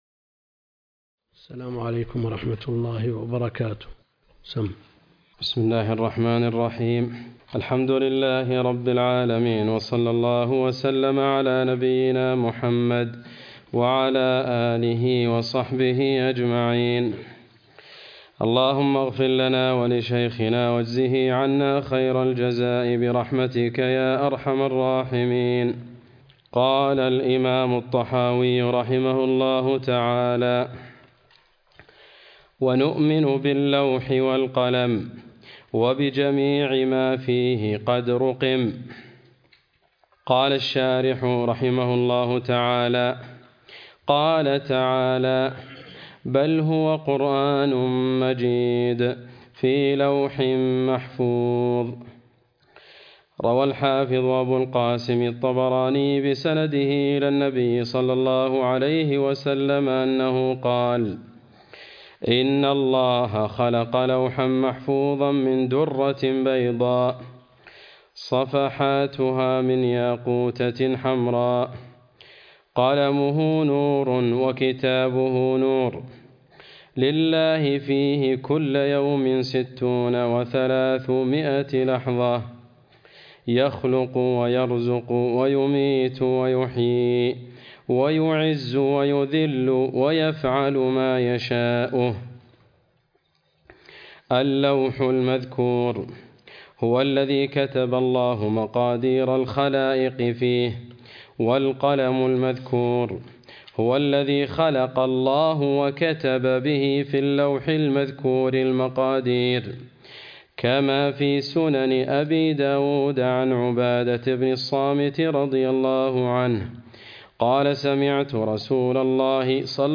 عنوان المادة الدرس (37) شرح العقيدة الطحاوية تاريخ التحميل السبت 21 يناير 2023 مـ حجم المادة 28.63 ميجا بايت عدد الزيارات 229 زيارة عدد مرات الحفظ 110 مرة إستماع المادة حفظ المادة اضف تعليقك أرسل لصديق